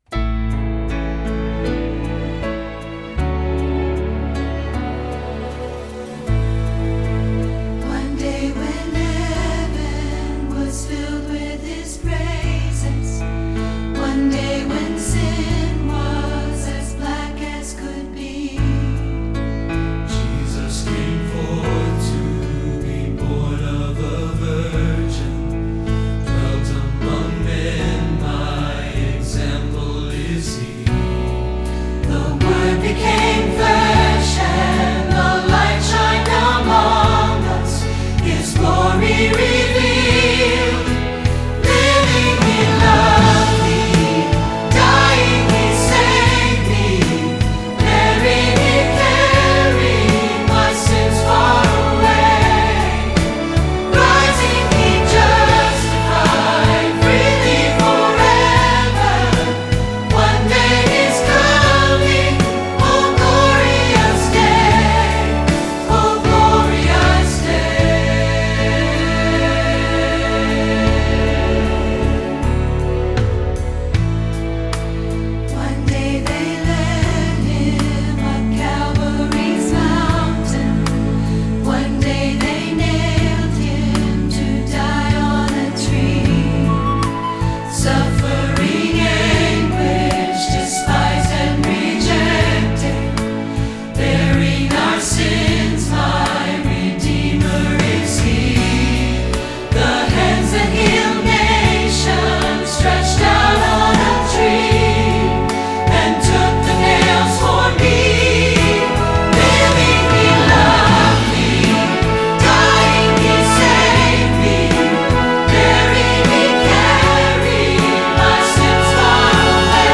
Choral Church
energetic and engaging new tune
SAB